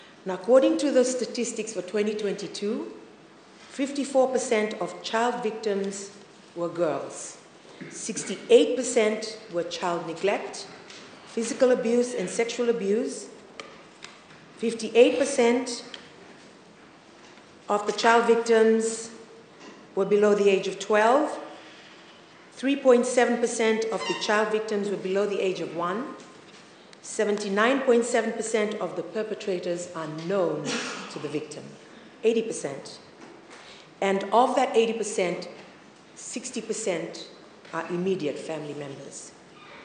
While delivering her ministerial statement in parliament this morning, she highlighted that 83 percent of our children are facing some form of abuse or violence.